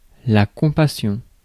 Ääntäminen
IPA: [kɔ̃.pa.sjɔ̃]